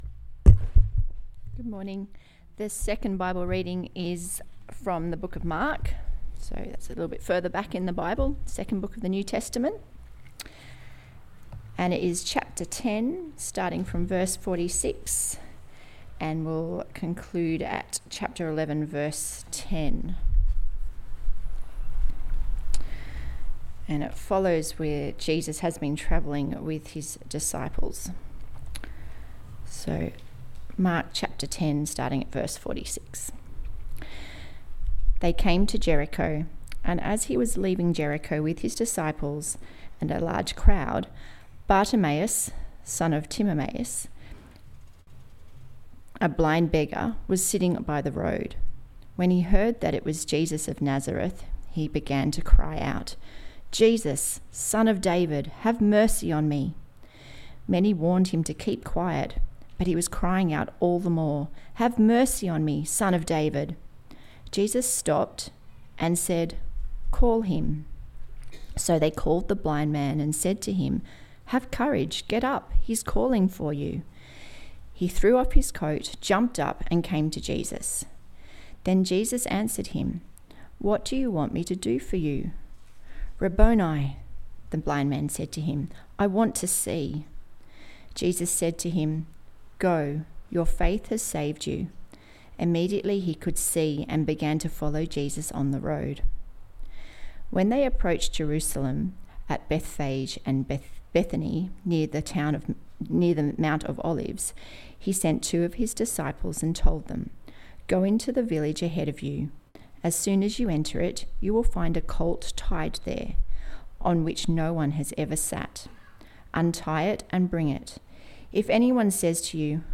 View Sermon details and listen